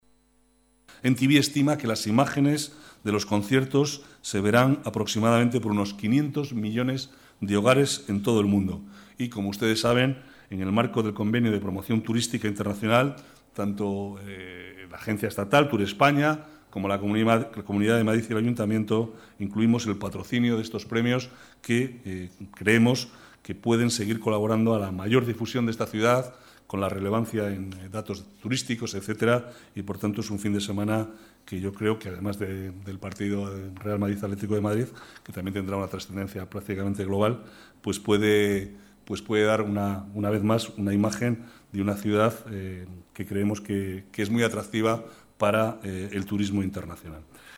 Nueva ventana:Declaraciones del vicealcalde, Manuel Cobo